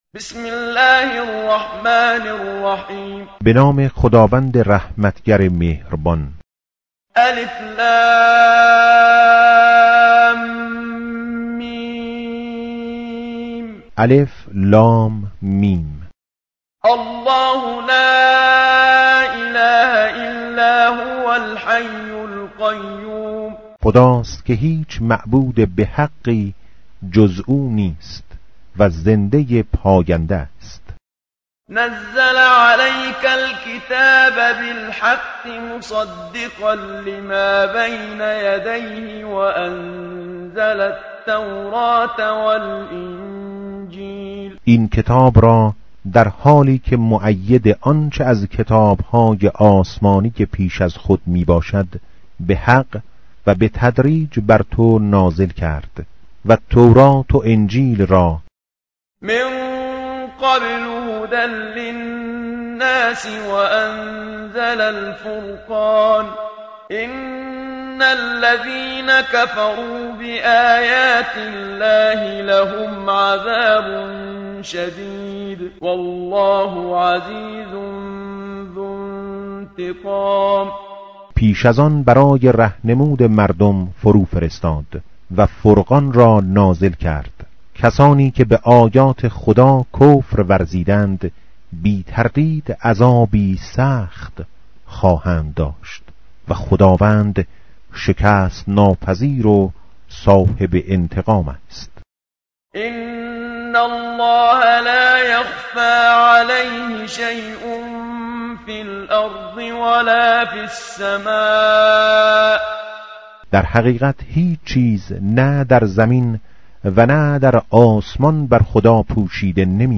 tartil_menshavi va tarjome_Page_050.mp3